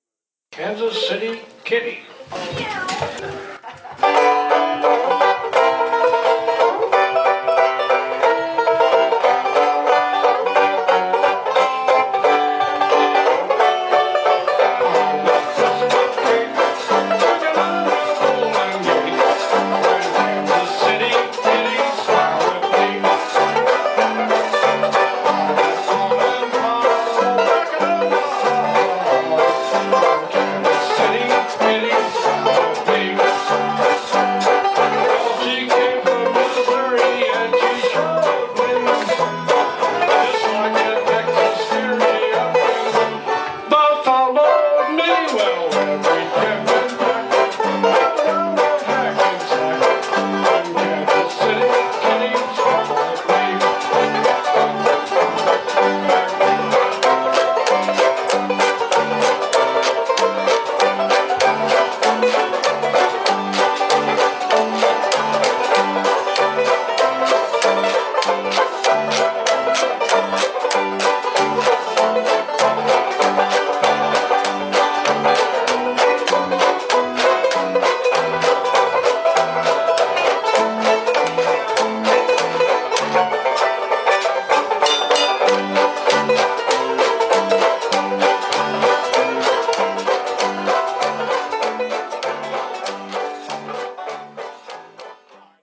8-beat intro.
Listen to the WineLand Banjo Band perform "Kansas City Kitty" (mp3)